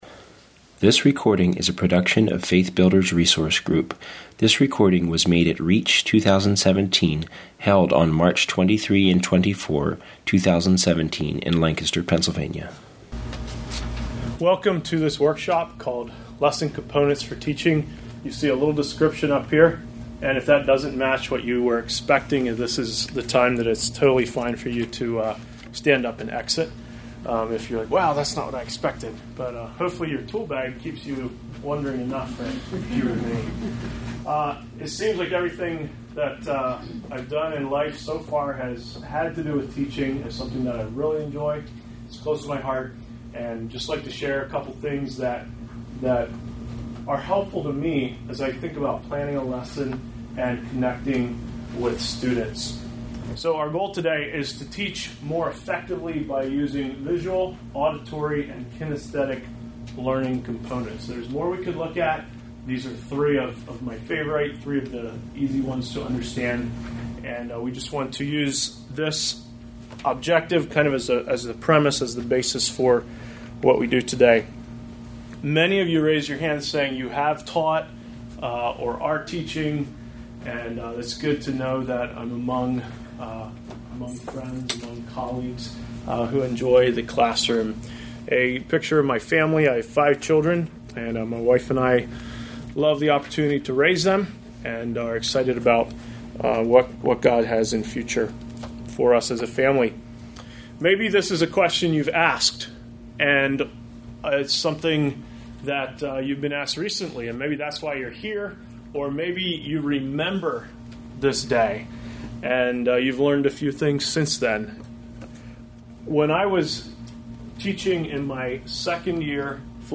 This workshop will explore visual, auditory, and kinesthetic learning styles and the value of incorporating them in our teaching. This will be an interactive workshop where attenders will participate in activities designed to illustrate the different learning styles.